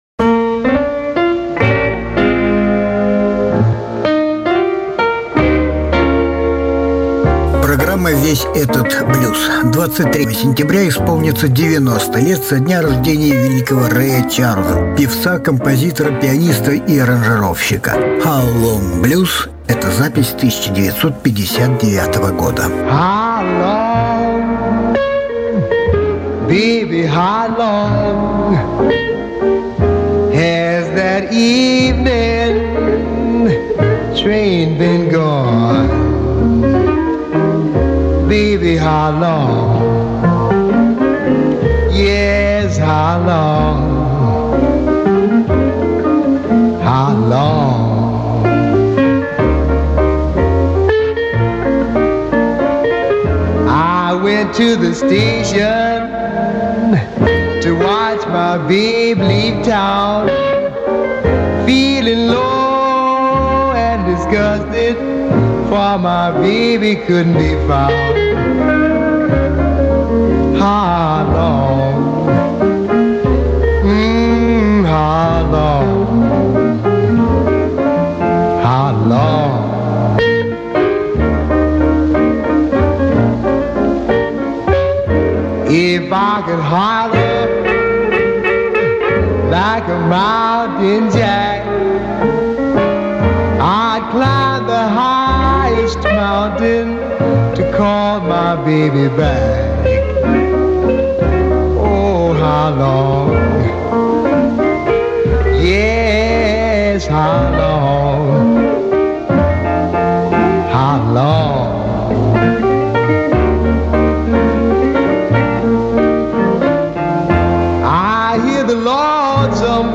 Ray Charles - американский эстрадный певец и пианист.